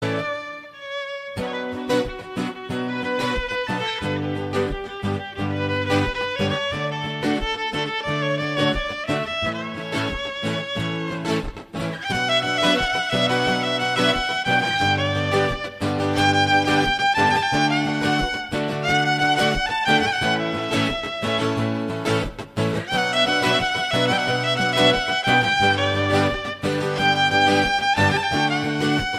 Instrumentalny